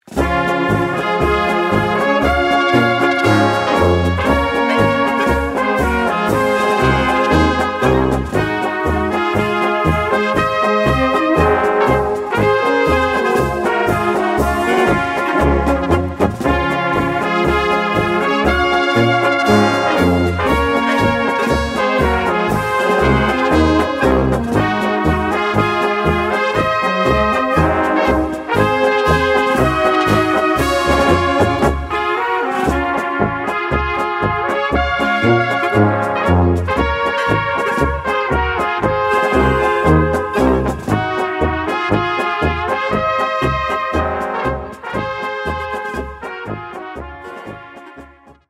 Gattung: Polka für Blasorchester
Besetzung: Blasorchester